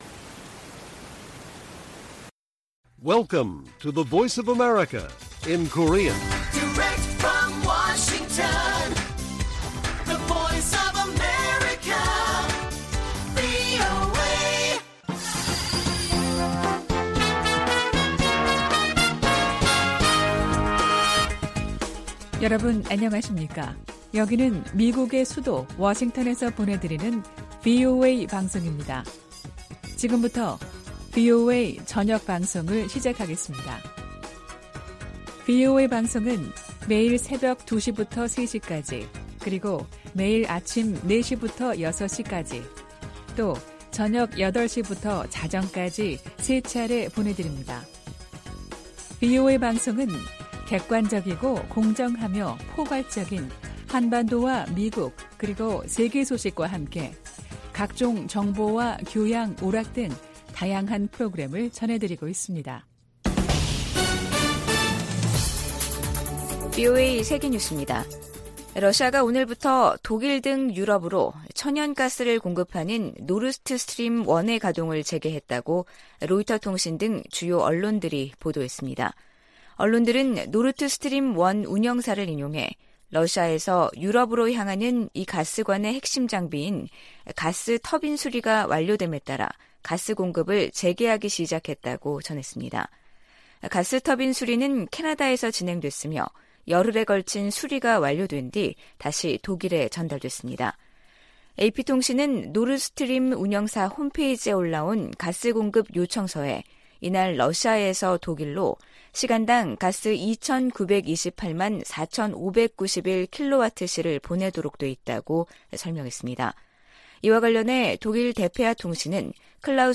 VOA 한국어 간판 뉴스 프로그램 '뉴스 투데이', 2022년 7월 21일 1부 방송입니다. 북한은 사이버 활동으로 수익을 추구하는 범죄조직이며, 이를 차단하는 것이 미국의 최우선 과제라고 백악관 고위 관리가 밝혔습니다. 백악관 국가안보회의(NSC) 측은 일본의 역내 안보 활동 확대가 북한 등 위협에 대응하는 데 큰 도움이 될 것이라고 말했습니다. 유럽연합(EU)은 북한이 우크라이나 내 친러시아 공화국들을 승인한 데 대해 국제법 위반이라고 비판했습니다.